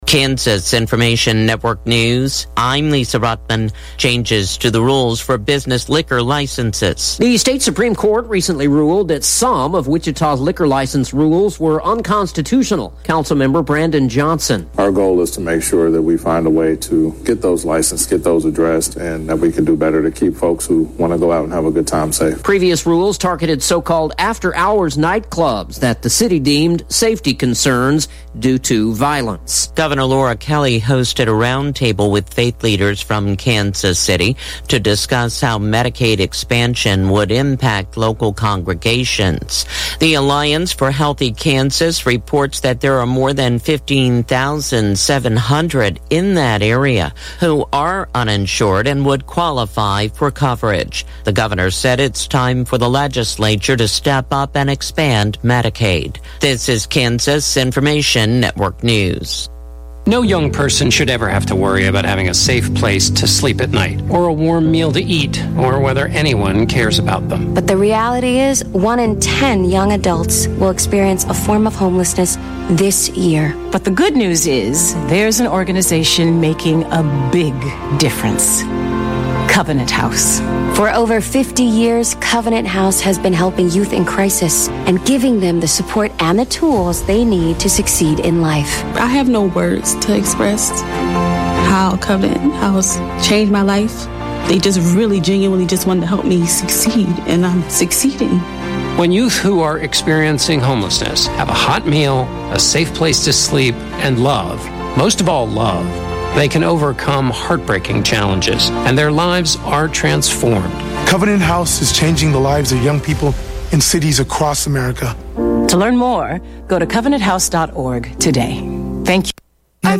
Classic Hits Q-106.7 & 102.5 News, Weather & Sports Update - 11/21/2023